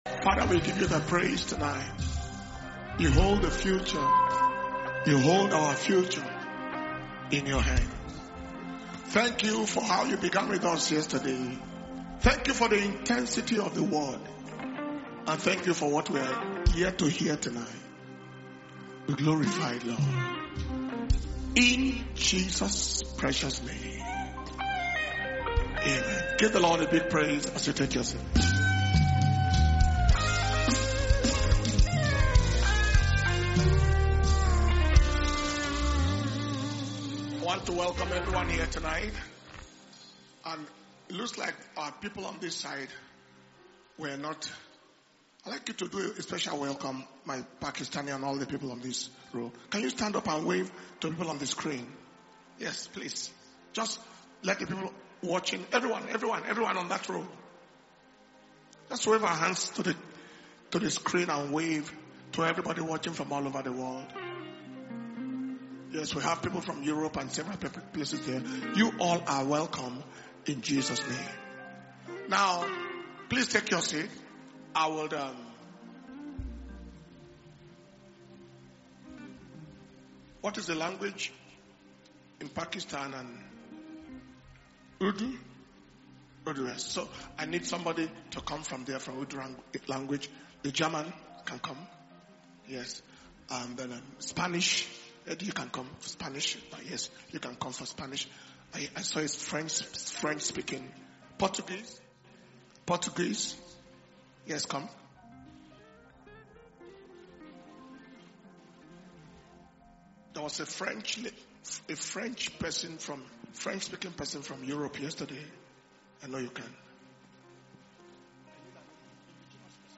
Dunamis International Ministers’ Flaming Fire Conference (IMFFC 2025) August